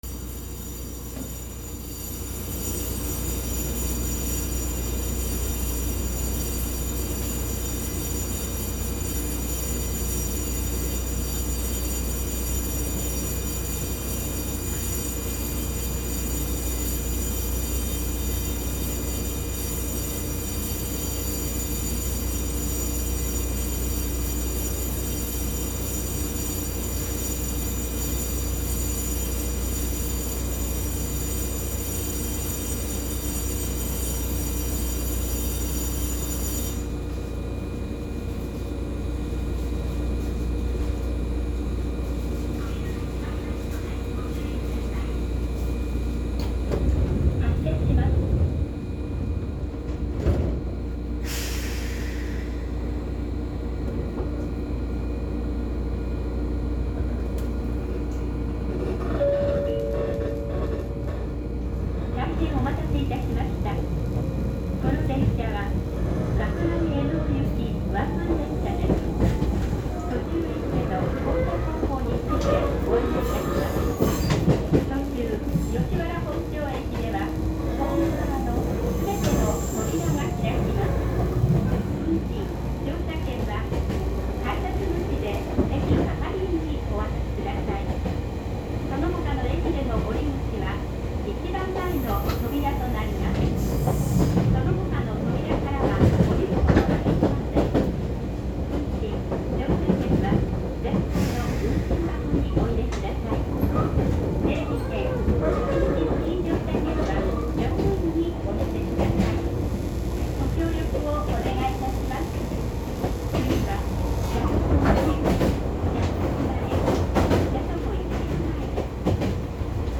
・8000系走行音
【岳南線】吉原→ジヤトコ前（4分42秒：8.62MB）
ごく普通の抵抗制御で、ワンマン運転故、自動放送もあります。
吉原→ジヤトコ前の走行音は自動放送更新後の物になります。